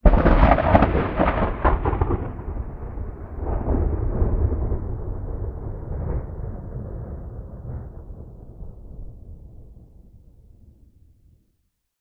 THUNDER_Clap_Rumble_02_stereo.wav